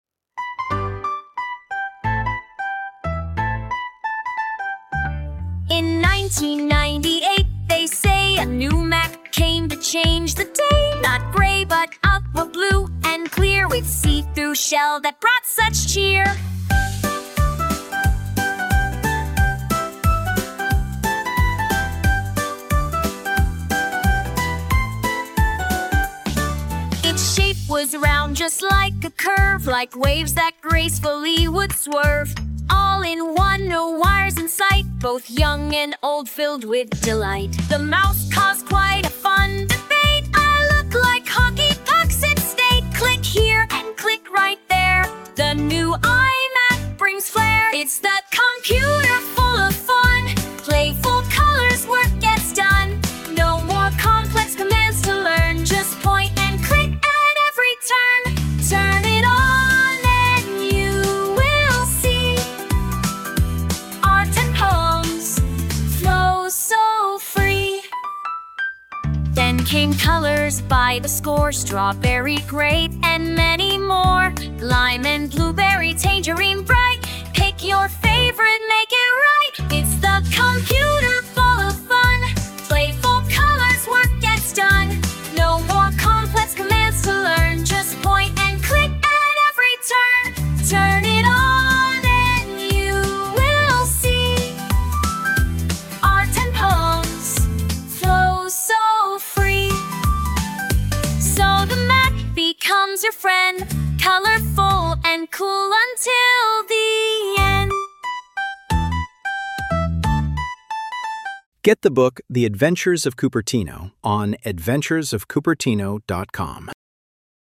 Bonus: iMac Round and Round – Ein kostenloses Bildungslied für Kinder über den legendären Bondi Blue iMac, den ersten bunten Computer, der Technologie zum Spaß machte!